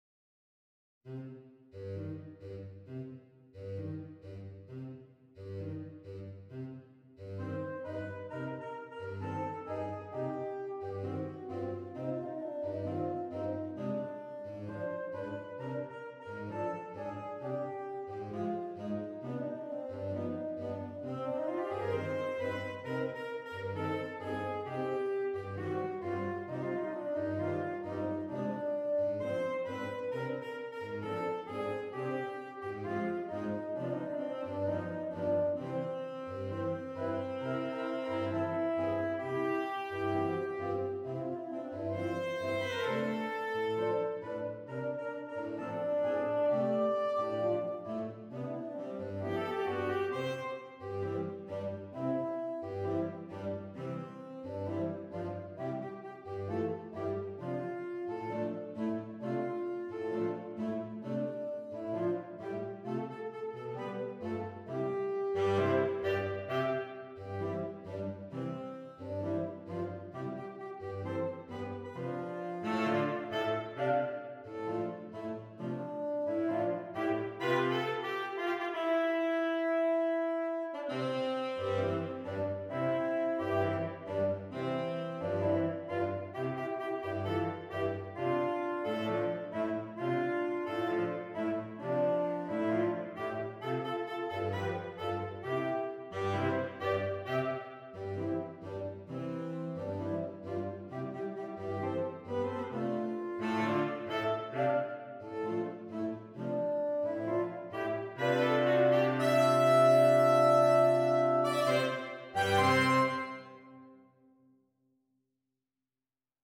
Saxophone Quartet (SATB or AATB)
Melody passes between the various voices.